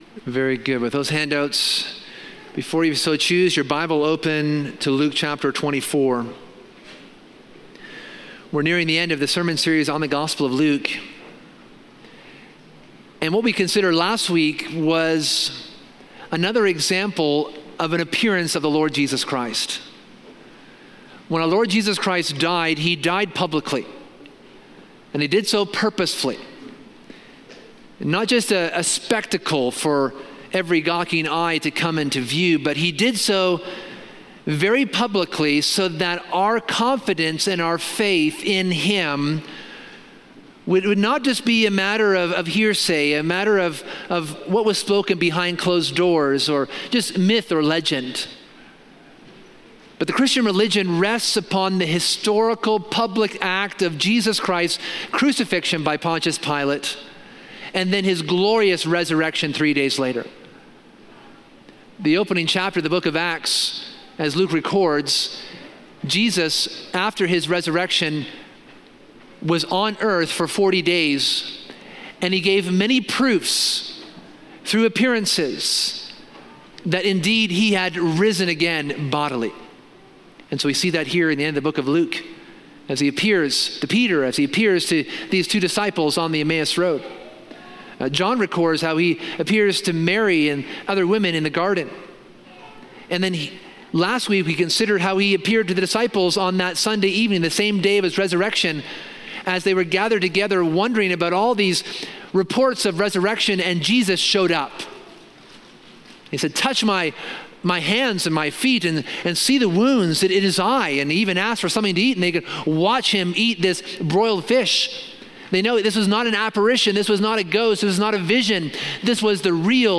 The sermon highlights three key points: the proclamation of Christ’s…